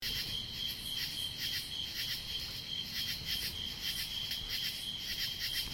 随机 " 蟋蟀的乡村田野之夜，附近有刺耳的蝉鸣和音调+偶尔的狗叫声1
描述：蟋蟀国家田野之夜与附近的蝉蝉和口气+偶尔吠叫dog1.flac
标签： 蟋蟀 晚上 国家
声道立体声